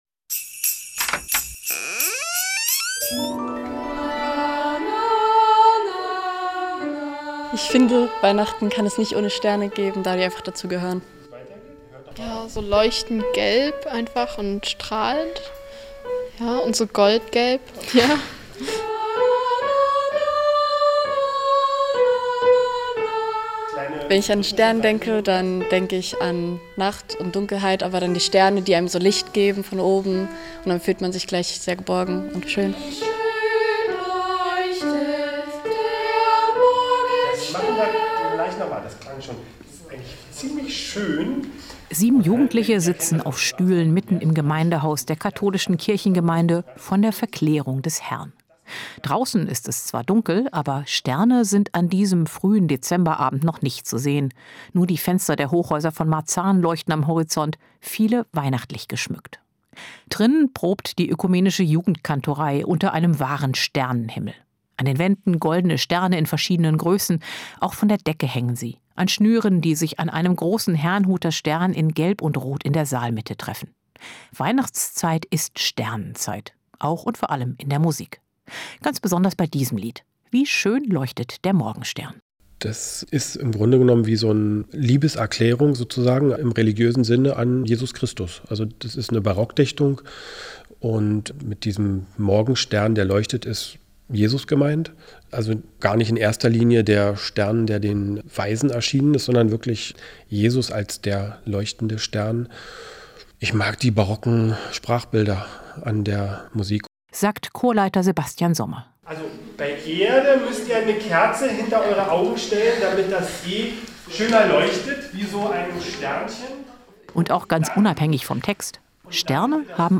Sternenklänge zum Heiligen Abend: Für unser 24.